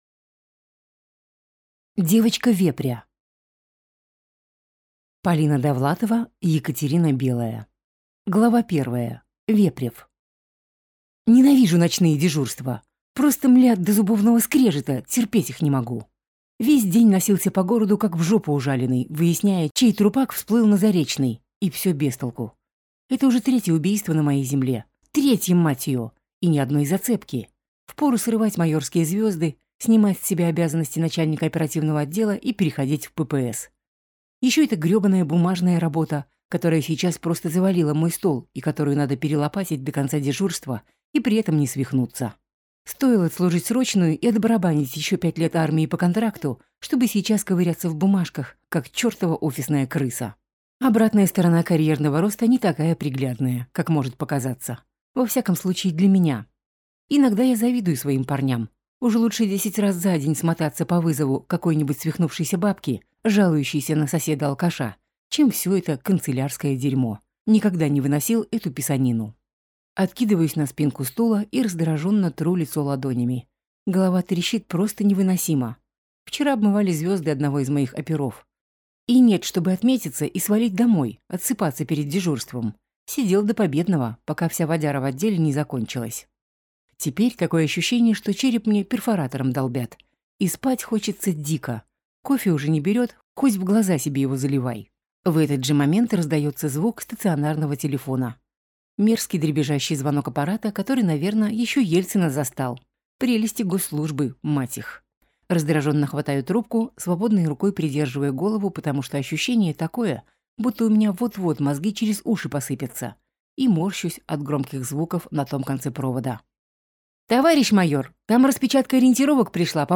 Аудиокнига Девочка вепря | Библиотека аудиокниг